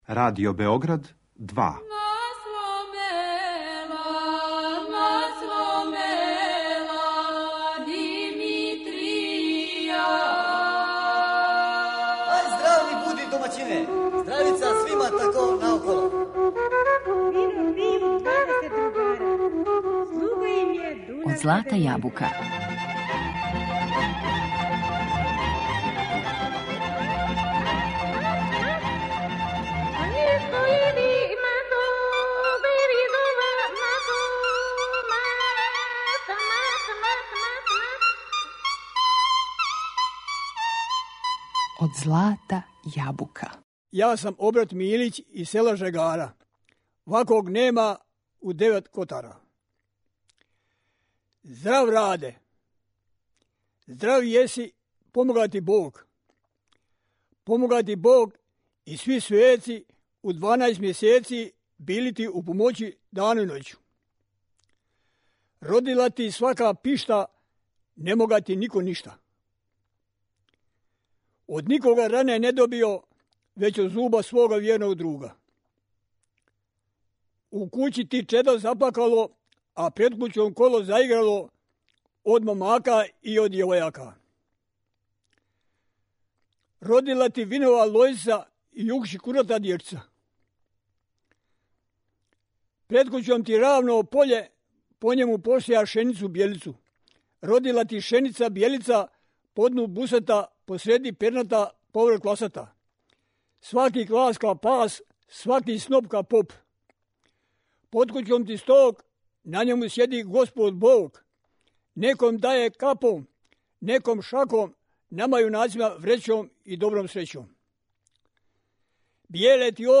'Традиционално пјевање, свирка на диплама, гуслање и приповедање из Жегара у Далмацији'
гуслање
који је снимљен у октобру 2014. године у студију VI Радио Београда.